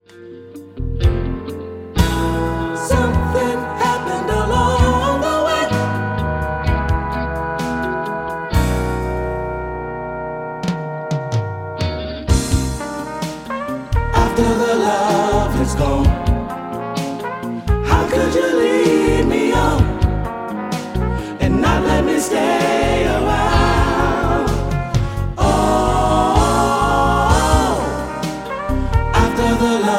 Backing track files: 1970s (954)